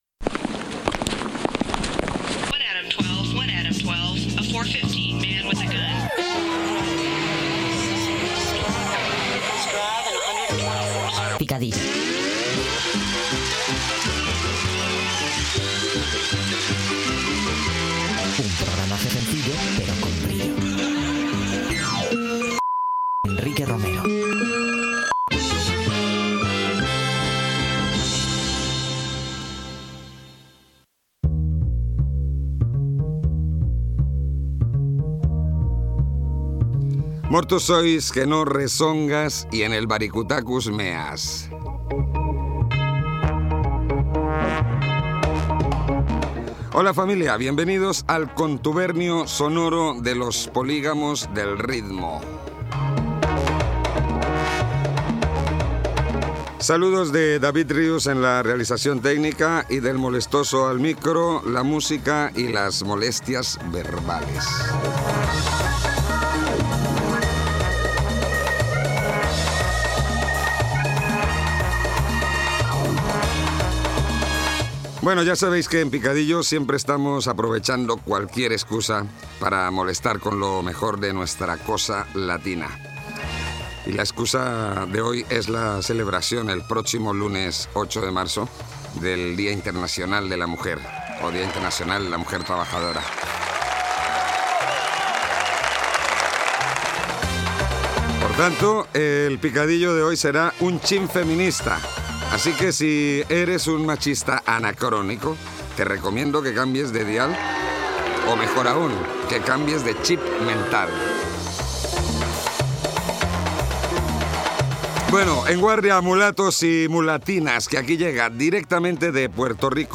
Careta, presentació del programa emès dos dies abans del Dia de la Dona, tema musical, comentari i tema musical
Musical
FM